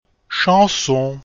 Sag laut [ong, ang, äng], halte dabei den Kopf etwas nach hinten. Sprich das g aber nicht aus!
chanson.mp3